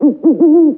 A_OWL1.mp3